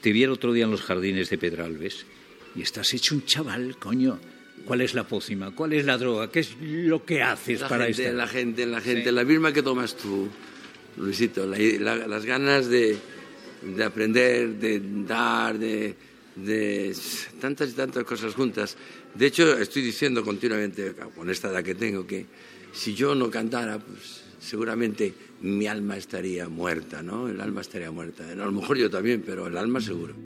Fragment d'una entrevista al cantant Julio Iglesias que estava actuant a Espanya
Info-entreteniment